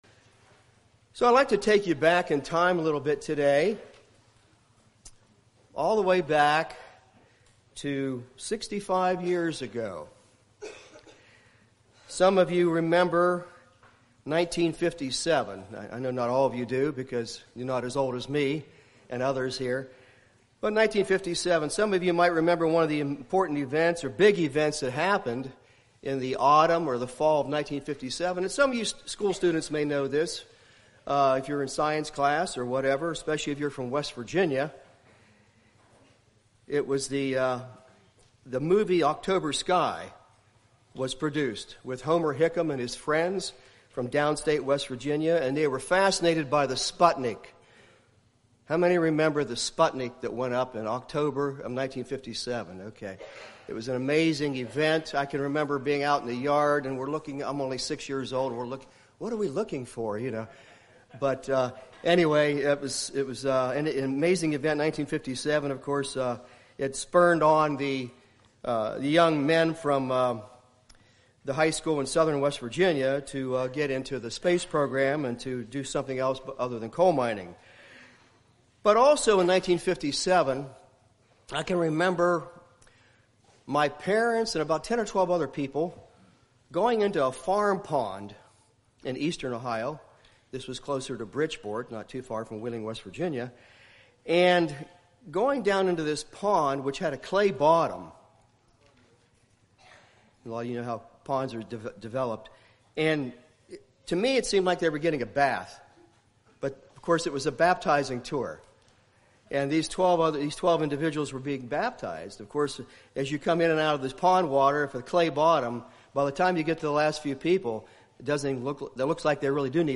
Sermons
Given in North Canton, OH Sugarcreek, OH